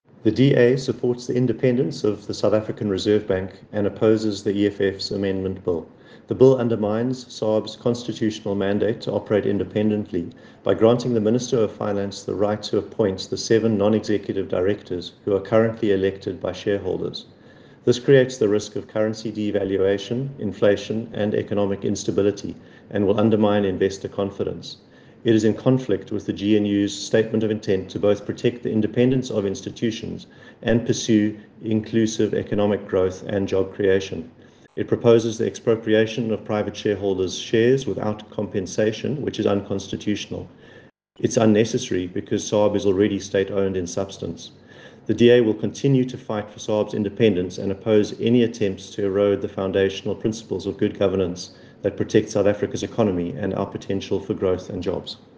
soundbite by Andrew Bateman MP